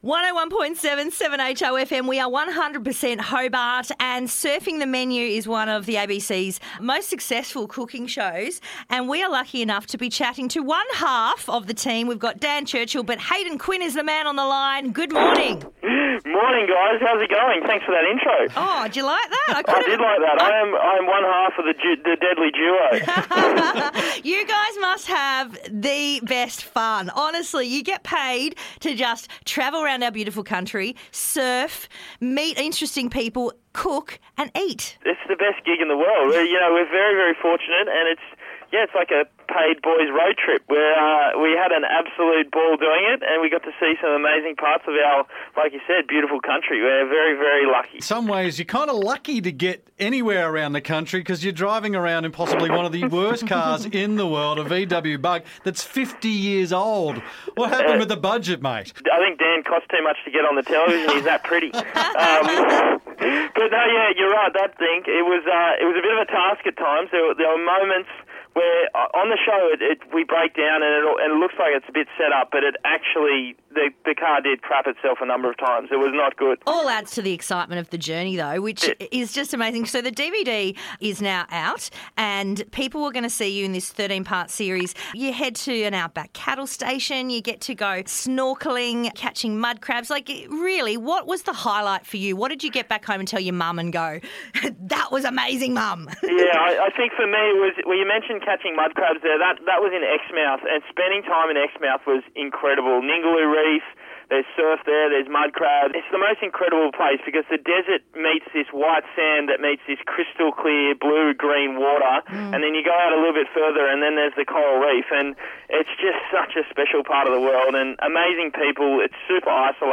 Hayden Quinn from Surfing The Menu had a chat with us about the latest series.